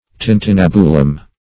Search Result for " tintinnabulum" : The Collaborative International Dictionary of English v.0.48: Tintinnabulum \Tin`tin*nab"u*lum\, n.; pl.